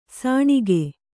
♪ sāṇige